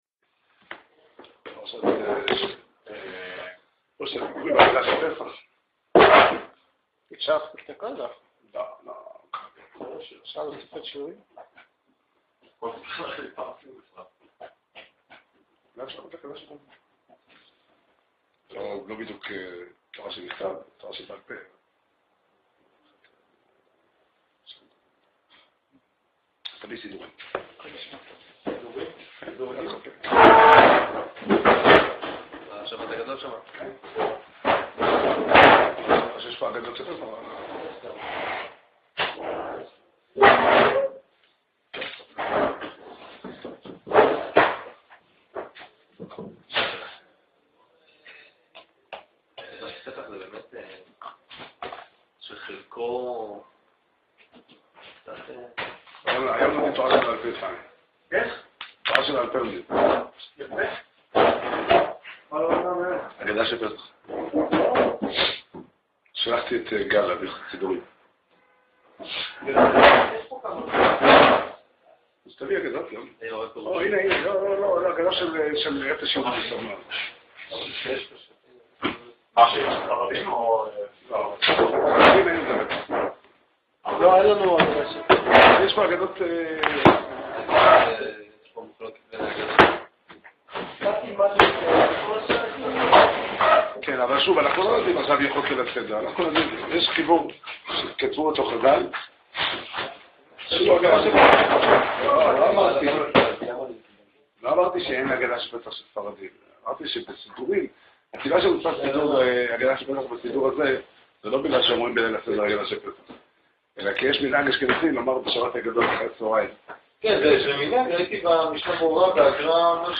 שיעור שנמסר בבית המדרש 'פתחי עולם' בתאריך כ"א אדר ב' תשע"ד